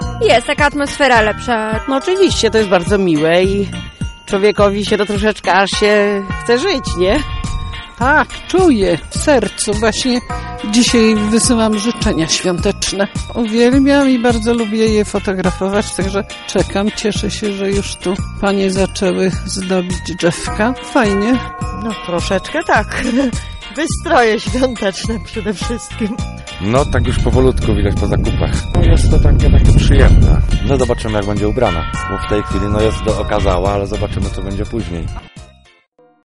Ulice naszego miasta zdobią już niewielkie stroiki, a w miniony piątek na żnińskiej starówce pojawiła się dziesięciometrowa choinka. W związku z tym zapytaliśmy mieszkańców Pałuk czy lubią kiedy miasto zyskuje świąteczny wygląd i czy dzięki temu czują już świąteczny klimat.